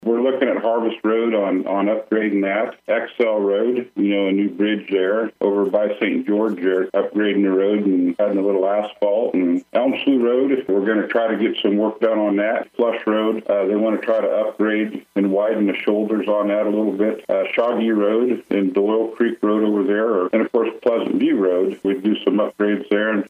Commissioner Greg Riat joined KMAN’s In Focus Tuesday, where he explained there are various projects around the county that this tax would help support.